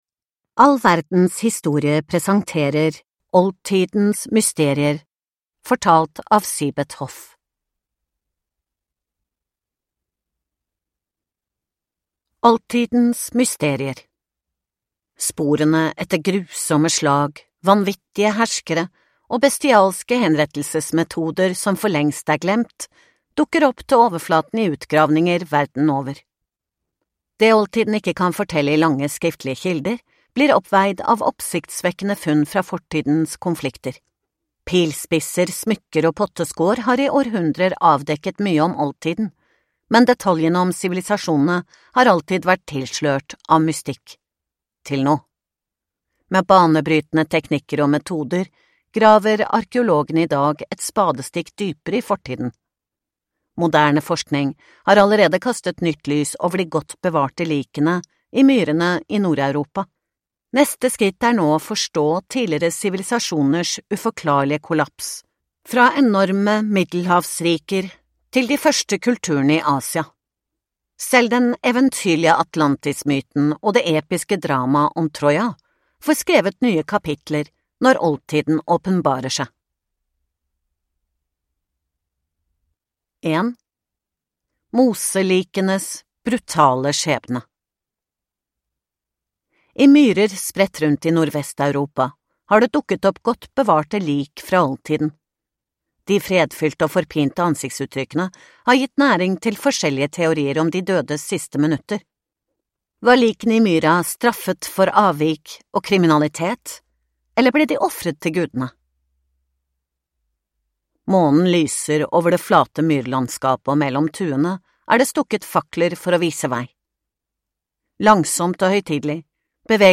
Oldtidens siste mysterier (ljudbok) av All verdens historie